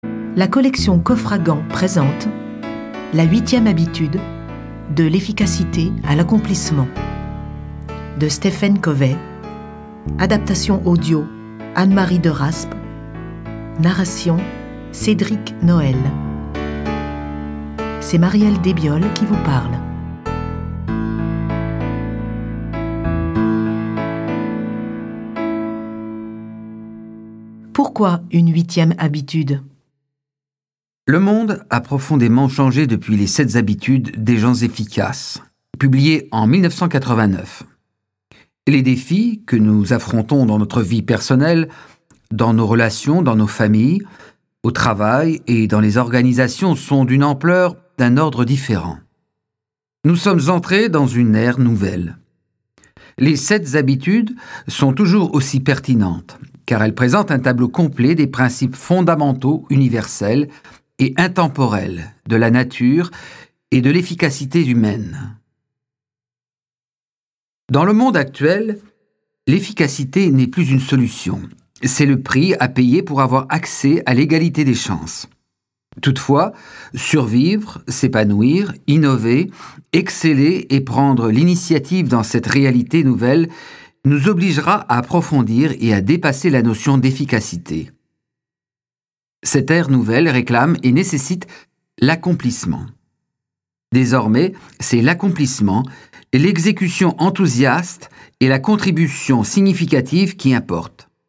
Click for an excerpt - La 8e habitude de Stephen COVEY